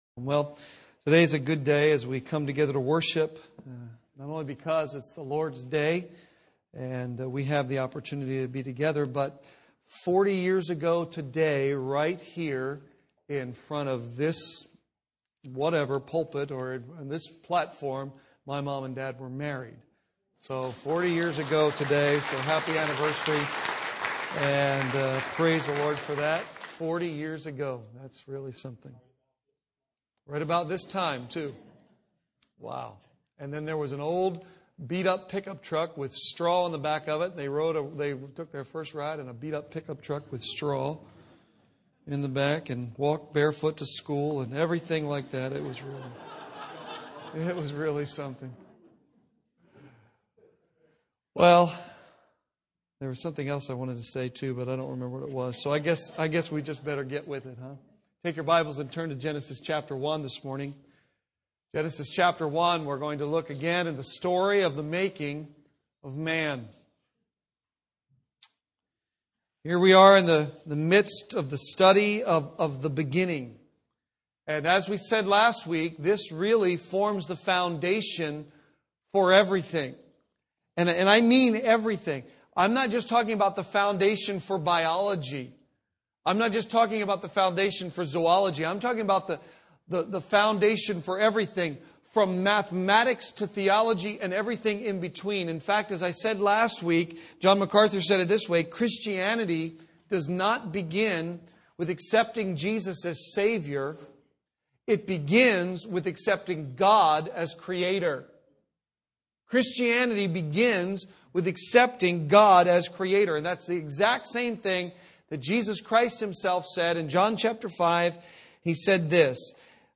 Sermons Archive - Page 86 of 95 - Calvary Bible Church - Wrightsville, PA